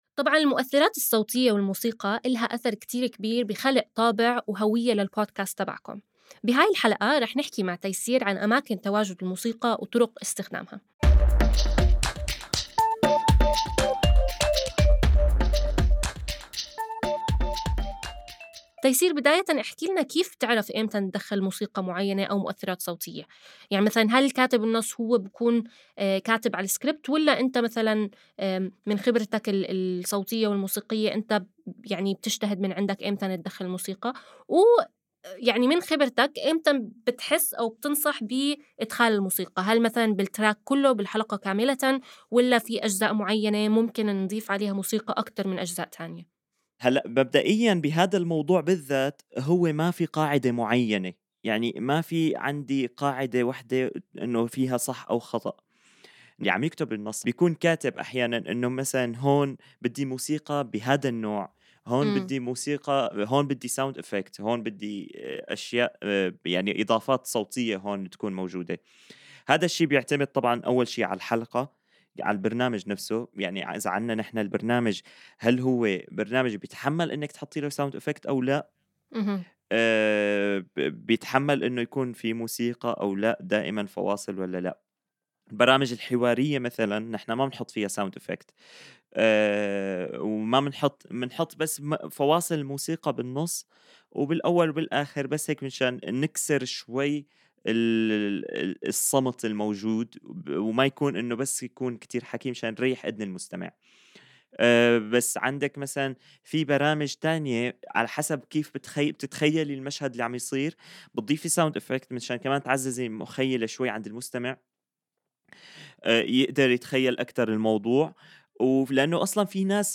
ابتداءً من الحلقة ١٤ وحتى نهاية المساق لن تجدوا تفريغ لهذه الحلقات حيث ستكون الحلقات حوارية وتكمن قيمتها في الاستماع لها.